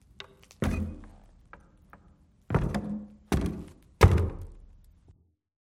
amb_fs_stumble_wood_04.mp3